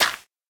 Minecraft Version Minecraft Version snapshot Latest Release | Latest Snapshot snapshot / assets / minecraft / sounds / block / suspicious_gravel / place1.ogg Compare With Compare With Latest Release | Latest Snapshot